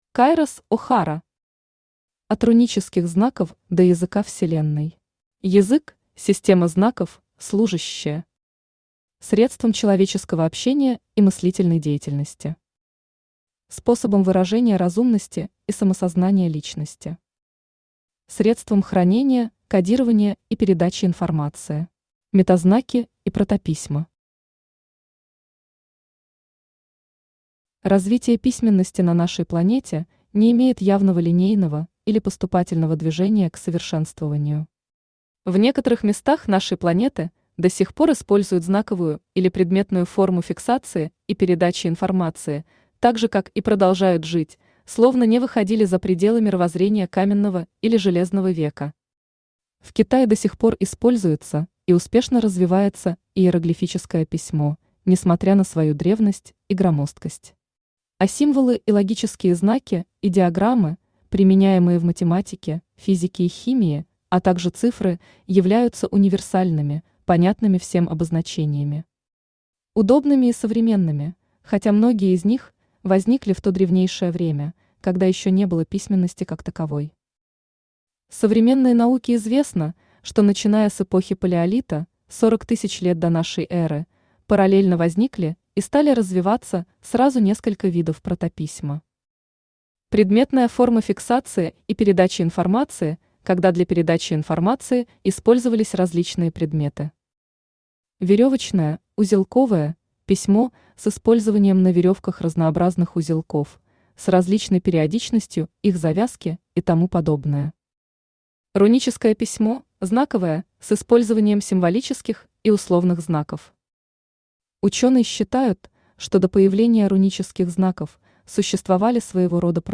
Аудиокнига От рунических знаков до языка Вселенной | Библиотека аудиокниг
Aудиокнига От рунических знаков до языка Вселенной Автор Кайрос О'Хара Читает аудиокнигу Авточтец ЛитРес.